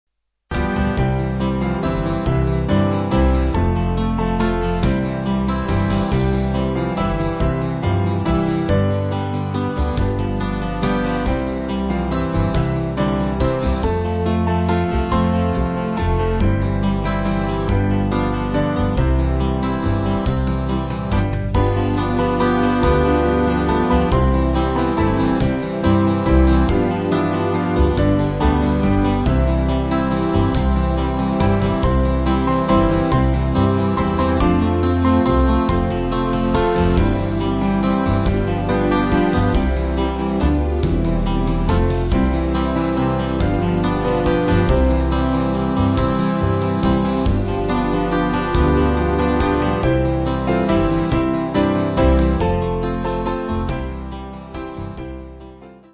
4/C-Db